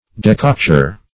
decocture - definition of decocture - synonyms, pronunciation, spelling from Free Dictionary
Decocture \De*coc"ture\ (?; 135), n.